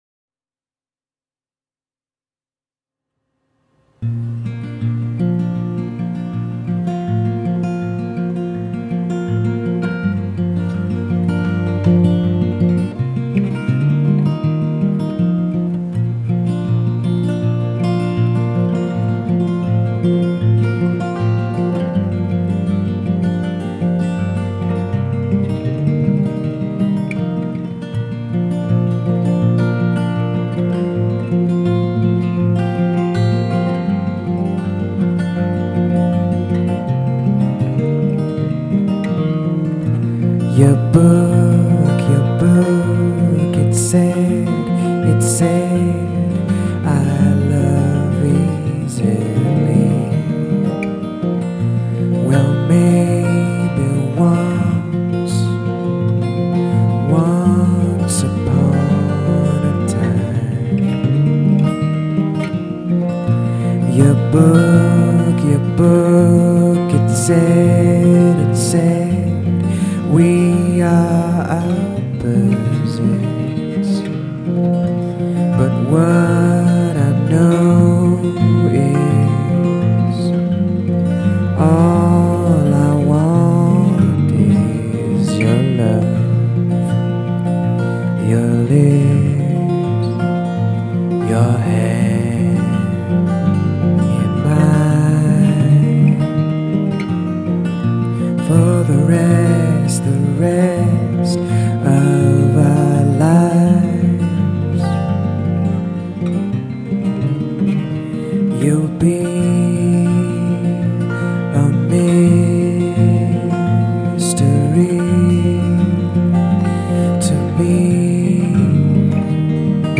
New Song.
I didn’t mean it to sound so David Gray-ish, but it kind of does. That, and Harrod and Funck.